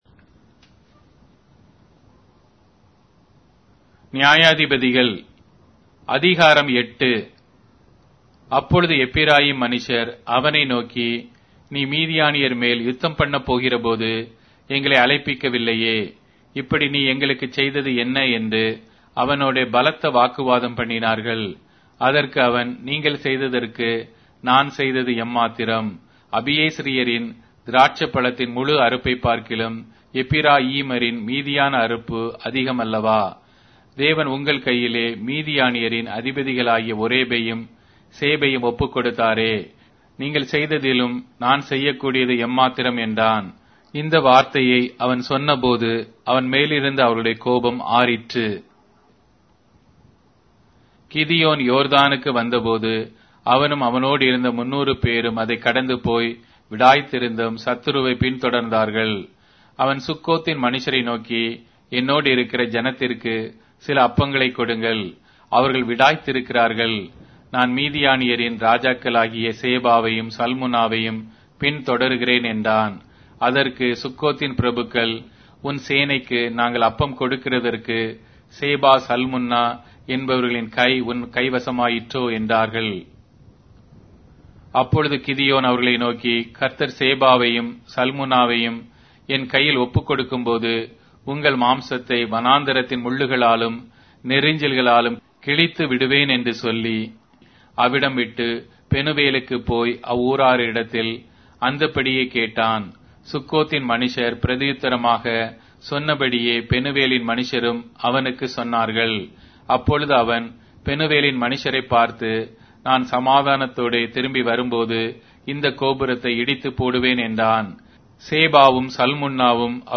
Tamil Audio Bible - Judges 15 in Urv bible version